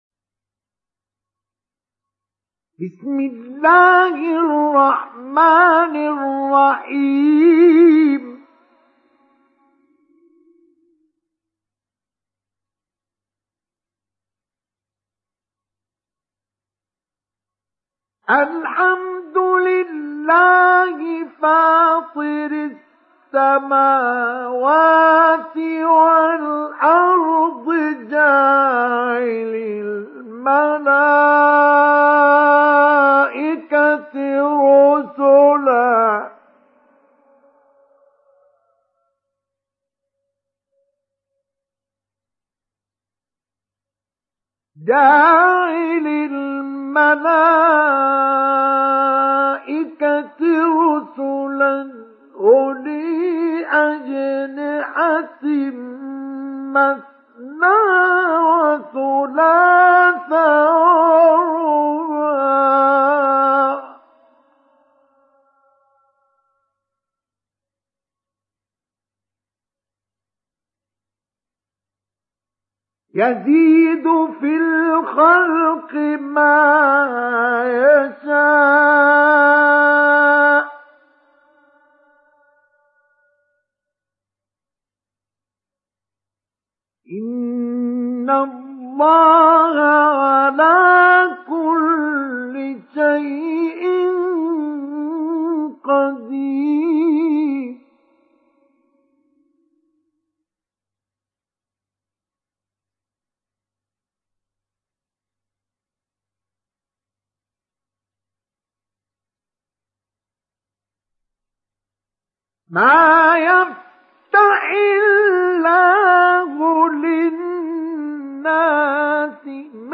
Télécharger Sourate Fatir Mustafa Ismail Mujawwad